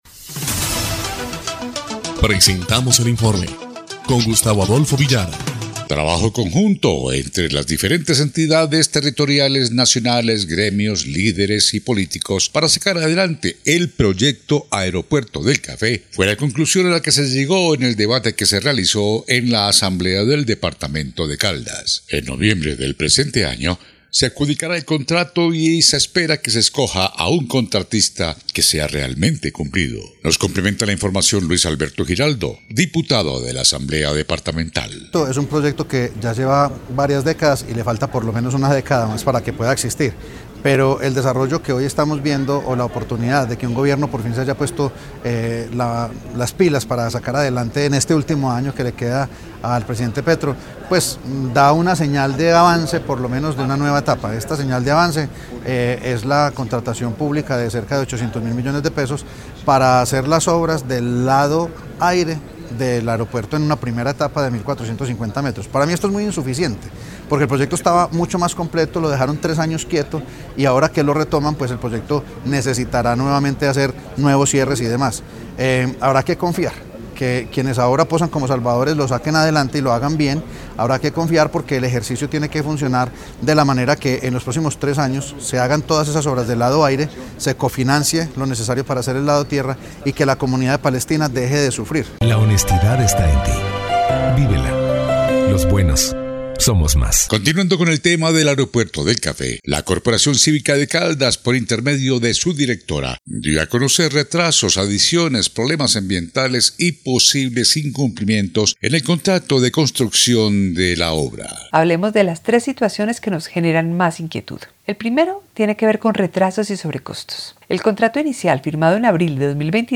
EL INFORME 3° Clip de Noticias del 23 de abril de 2025